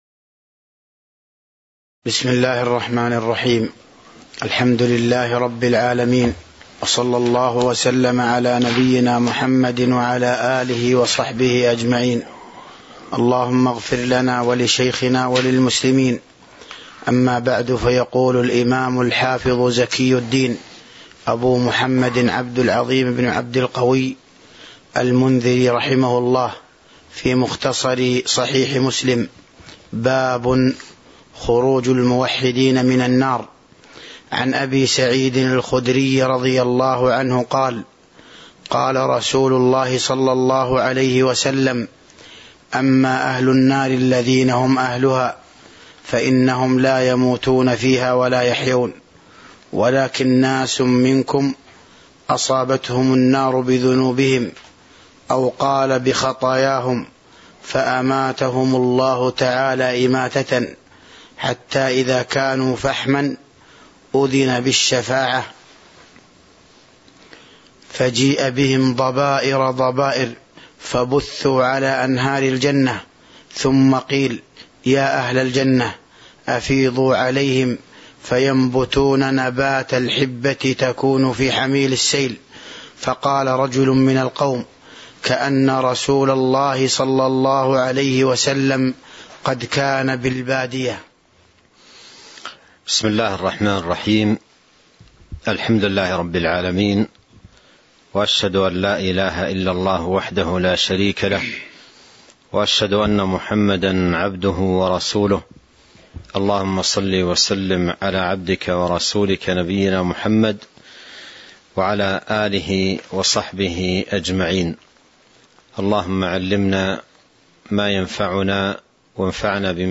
تاريخ النشر ١٧ ربيع الأول ١٤٤٢ هـ المكان: المسجد النبوي الشيخ